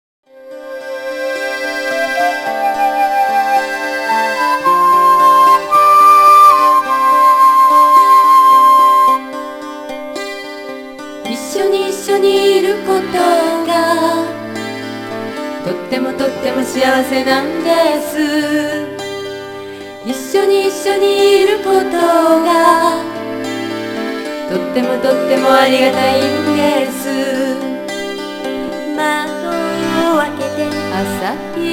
（インストゥルメンタル）